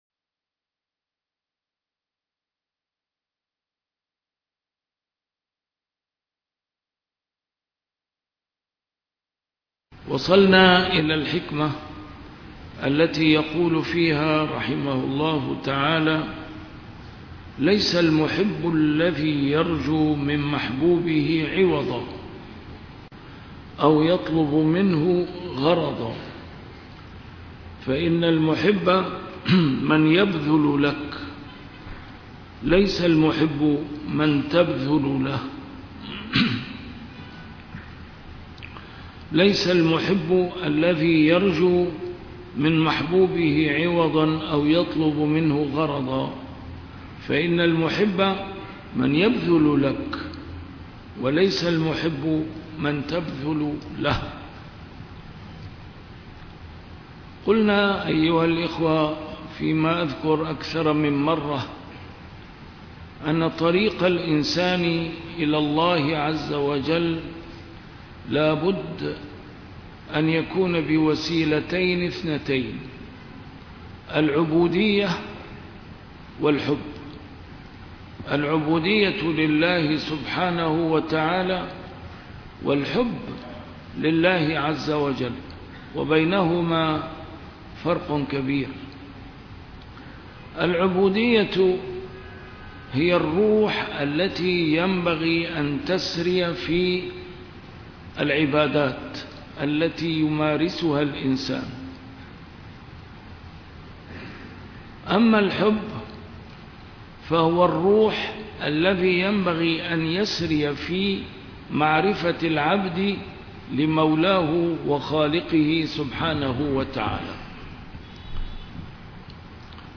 A MARTYR SCHOLAR: IMAM MUHAMMAD SAEED RAMADAN AL-BOUTI - الدروس العلمية - شرح الحكم العطائية - الدرس رقم 264 شرح الحكمة رقم 242